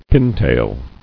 [pin·tail]